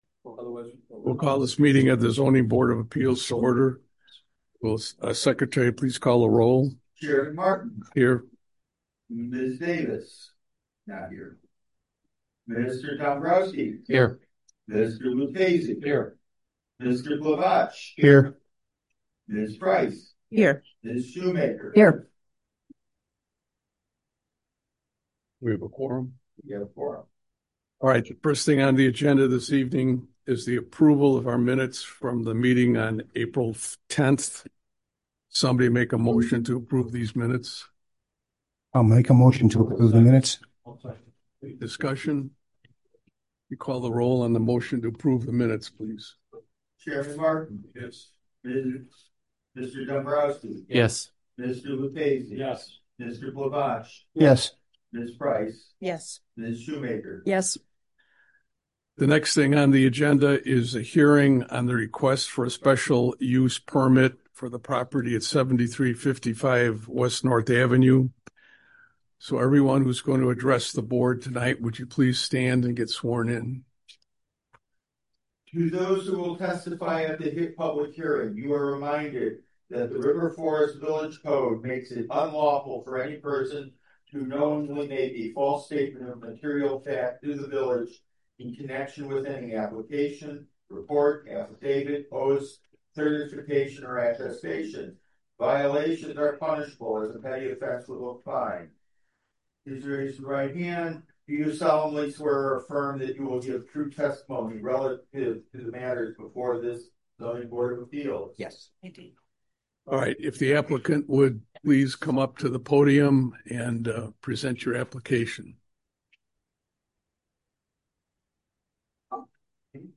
Special Meeting of the Zoning Board of Appeals
Village Hall - 1st Floor - COMMUNITY ROOM - 400 Park Avenue - River Forest - IL